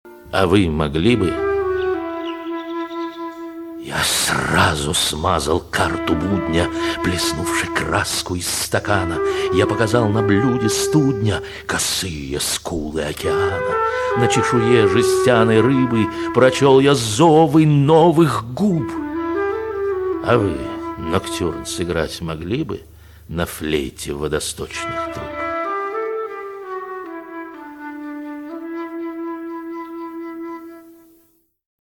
Владимир-Маяковский-А-вы-могли-бы-художественная-обработка.mp3